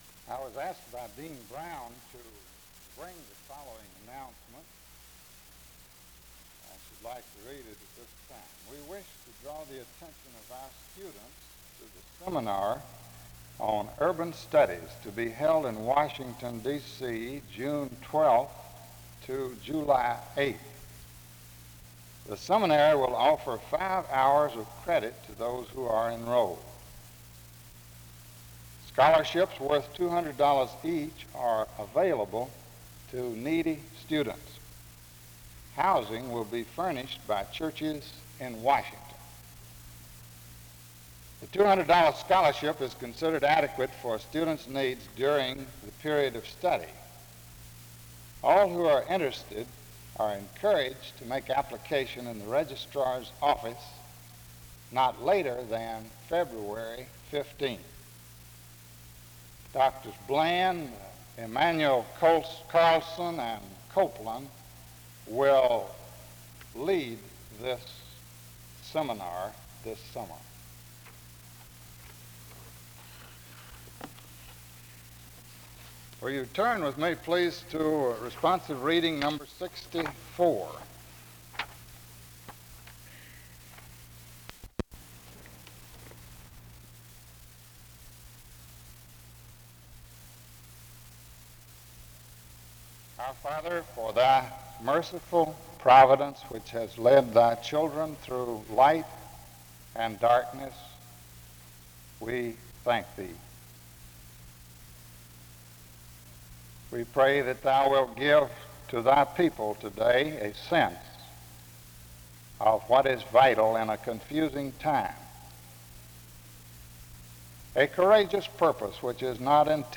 This service begins with an announcement at 0:00, followed by a prayer at 1:33.
At 3:46, there is a scripture reading from Matthew 10:37-39, followed by a song. The message begins at 9:54, with the subject of the qualifications of responding to the invitation to follow Jesus.